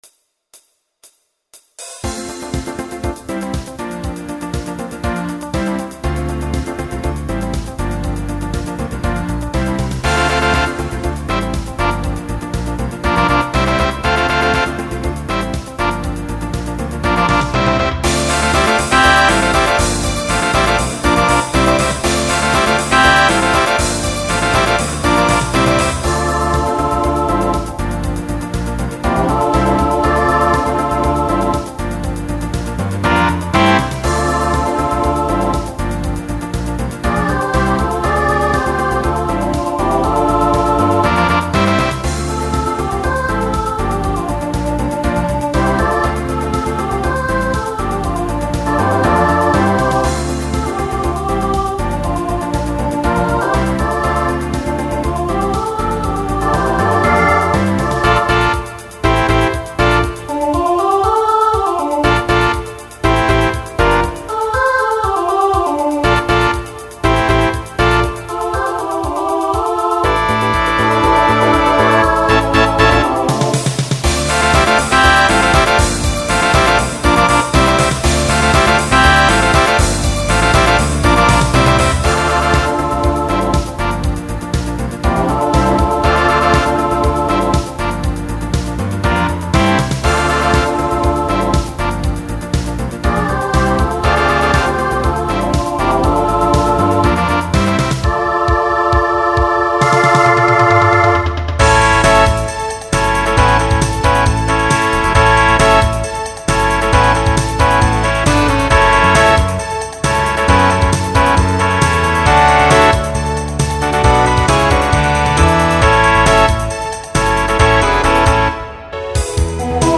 Genre Disco , Pop/Dance Instrumental combo
Transition Voicing SSA